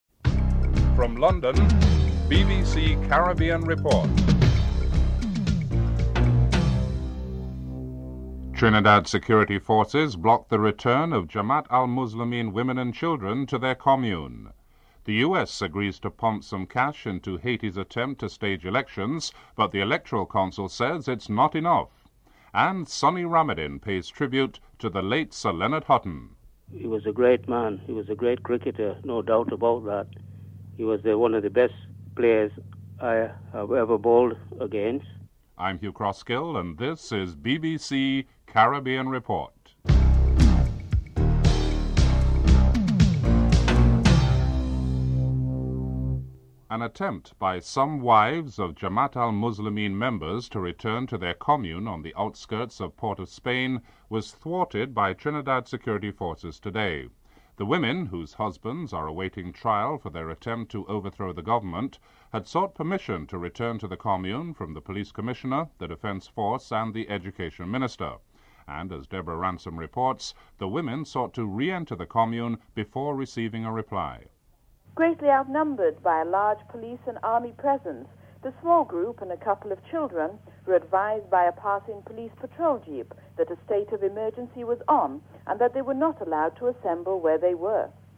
1. Headlines (00:00-00:45)